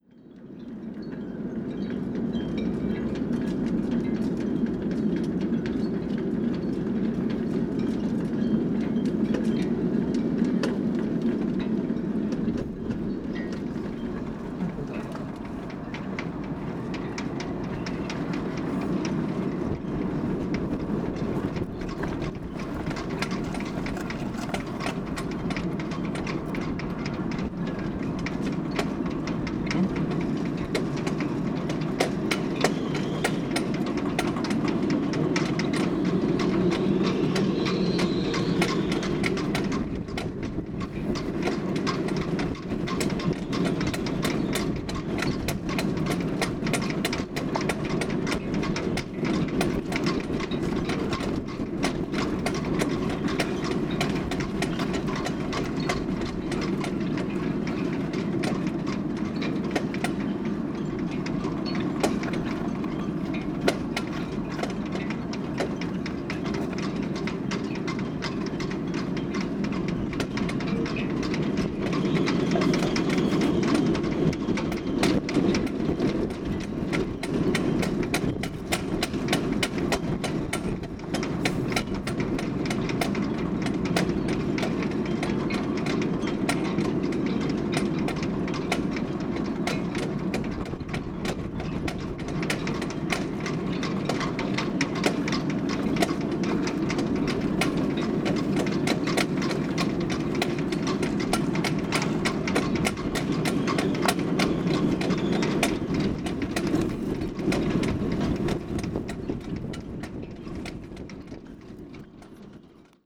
This is a compilation from the ongoing project ‘Situations and Circumstances’ as a series of field recordings that are made public in this release intending to be available for free download and potential reuse in new works of other artists.